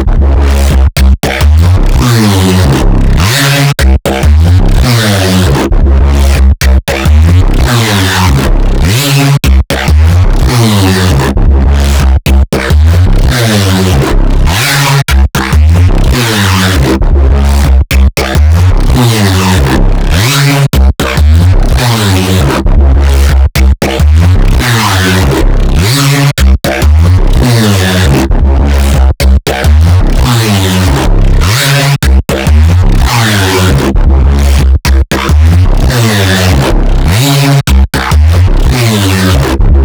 Not very easy to produce Noisia style drum'n'bass.
the first is a bit too digital, too much distortion? although I like where youre going with it
Yeah the oplm one is WAAAAYYY over board its not really sharp sounding if u know what I mean.